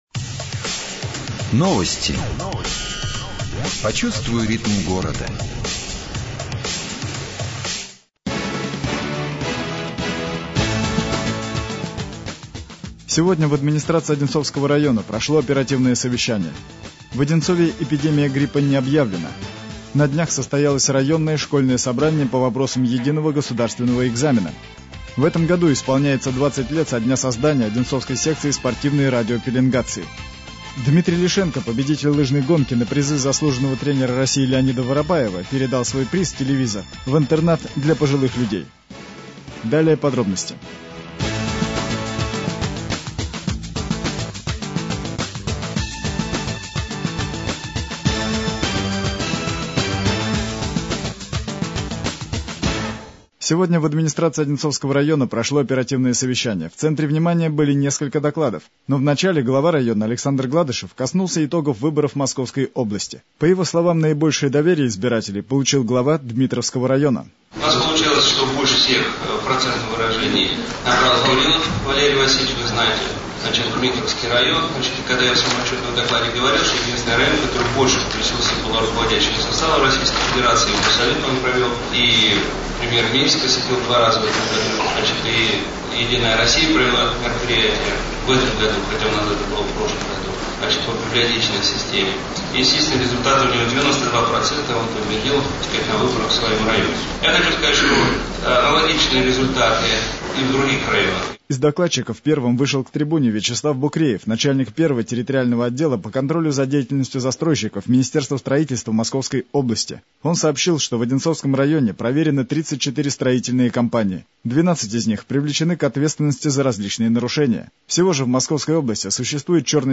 25 февраля состоялся ежегодный отчет Главы Одинцовского района об итогах социально-экономического развития. Вначале же к трибуне вышел заместитель председателя правительства Московской области Сергей Кошман.